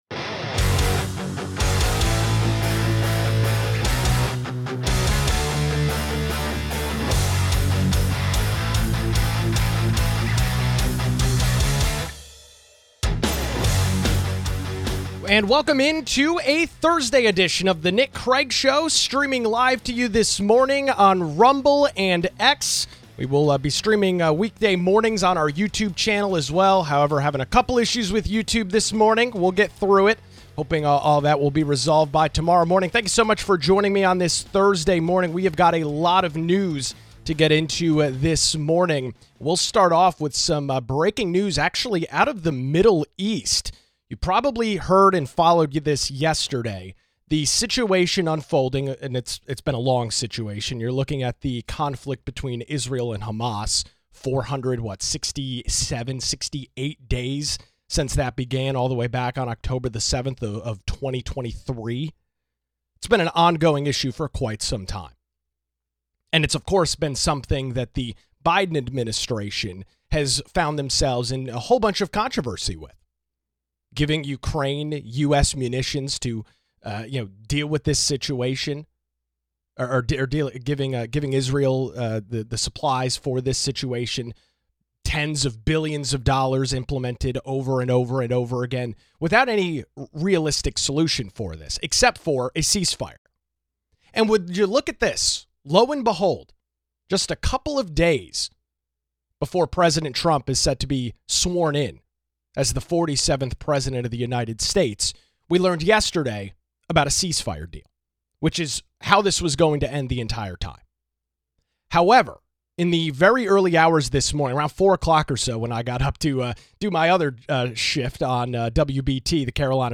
Guests include US Senator Ted Budd.